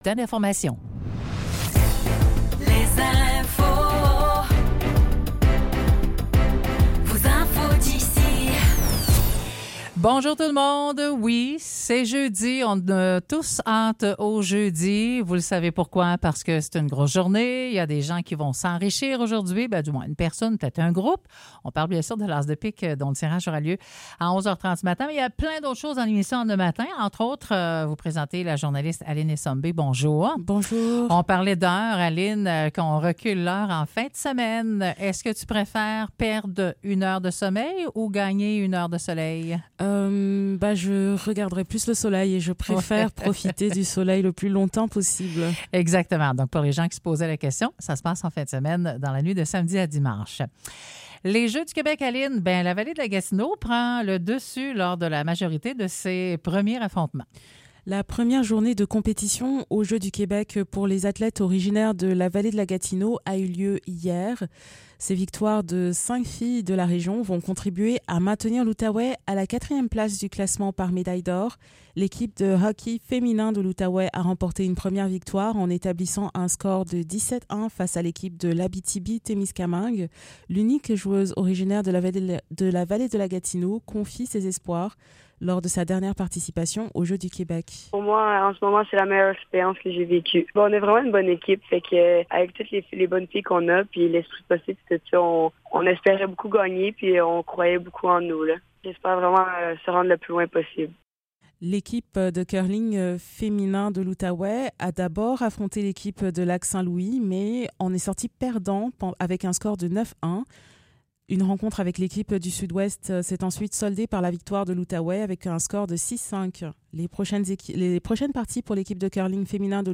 Nouvelles locales - 7 mars 2024 - 9 h